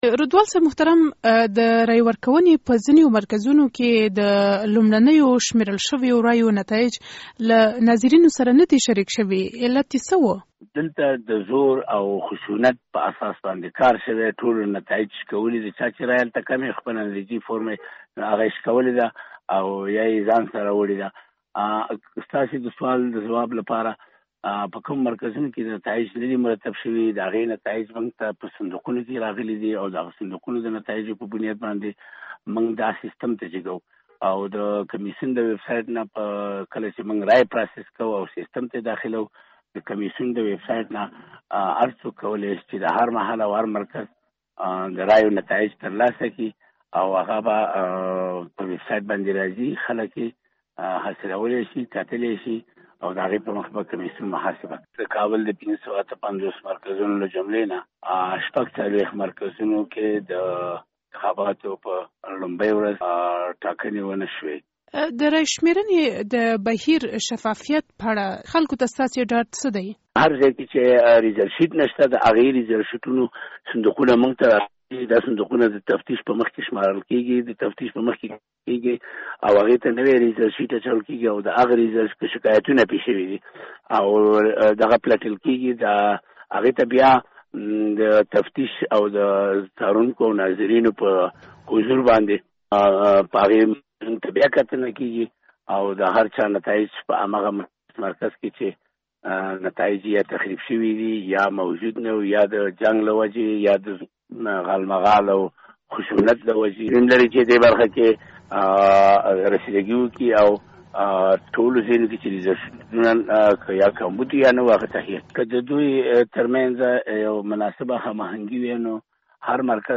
له ښاغلي رودوال سره مرکه